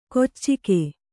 ♪ koccike